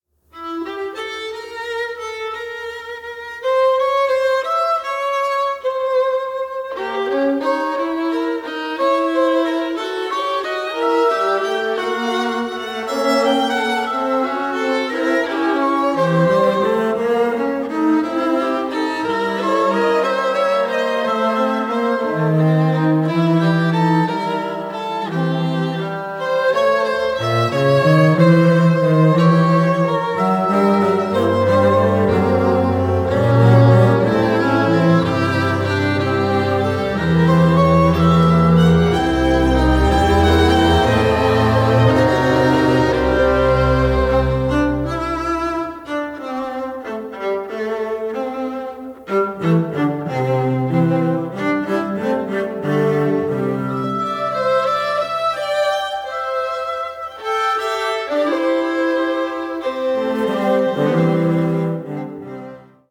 violin
viola
double bass